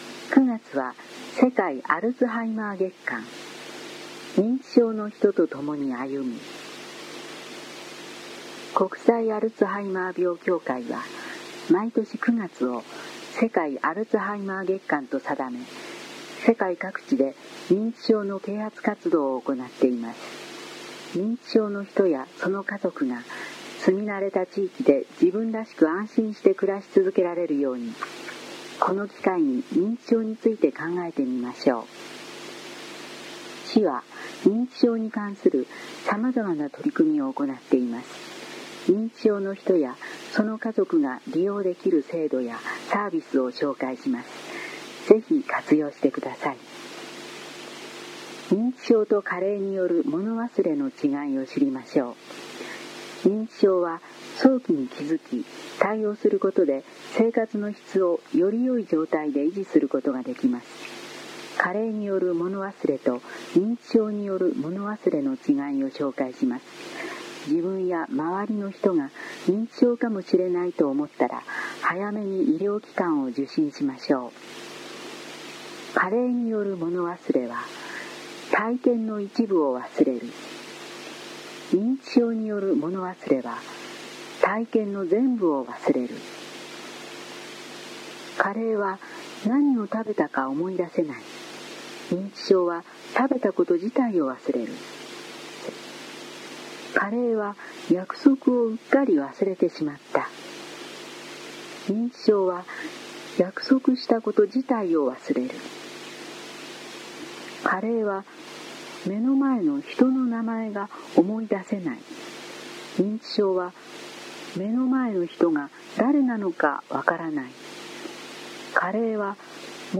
声の広報は目の不自由な人などのために、「広報いせさき」を読み上げたものです。
朗読
伊勢崎朗読奉仕会